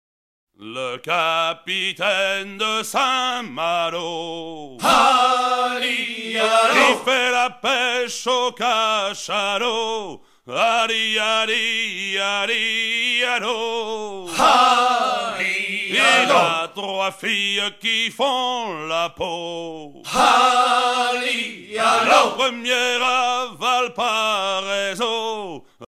gestuel : à hisser à grands coups
circonstance : maritimes
Genre laisse
Pièce musicale éditée